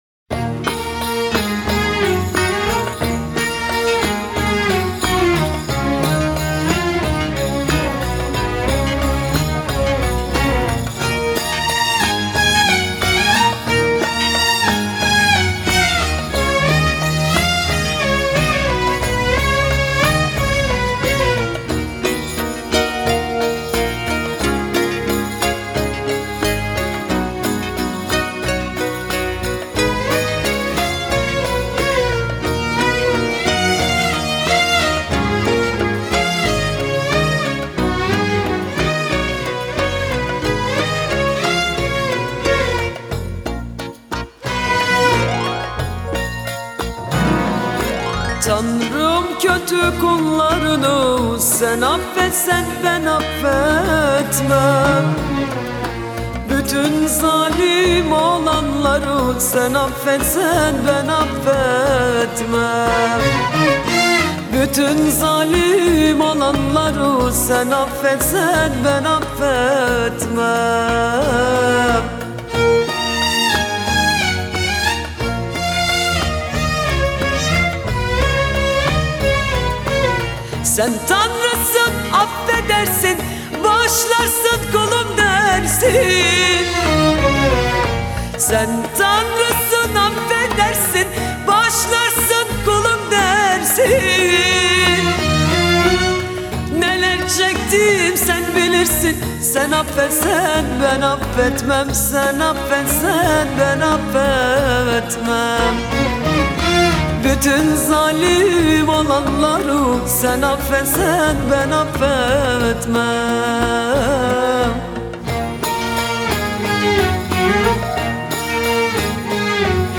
آهنگ ترکیه ای آهنگ غمگین ترکیه ای آهنگ نوستالژی ترکیه ای